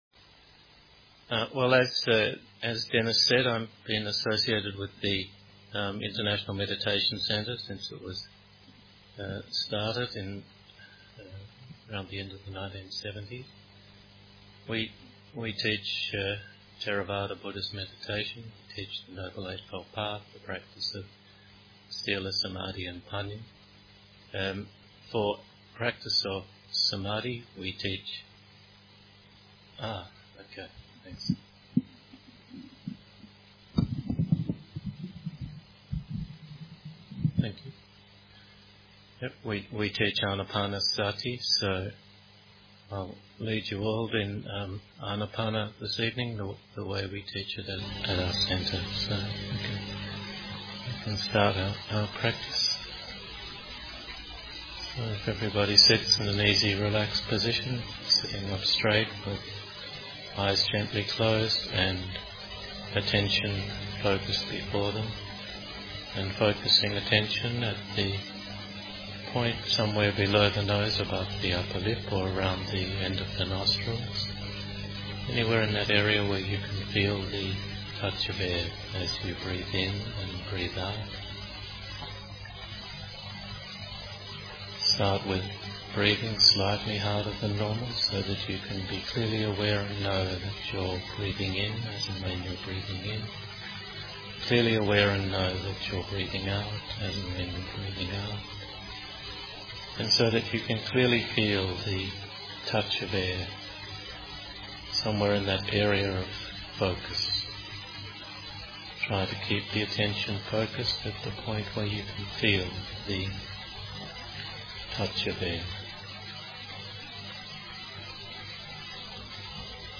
using the (free) utility “audacity” I’ve made a louder version of the soundtrack of the video (couldn’t reconnect the improved soundtrack with the videotrack - don’t know how/with what instrument).